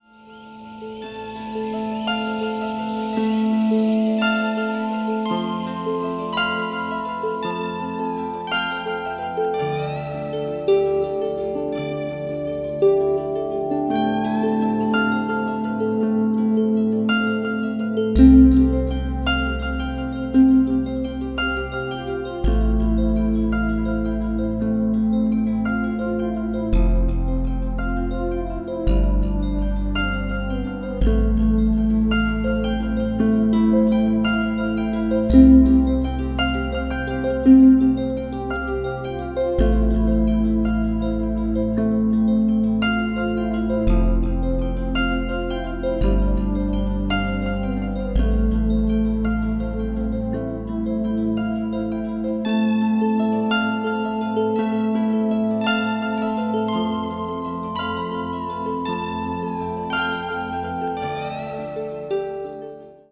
Recorded at the Royal Botanic gardens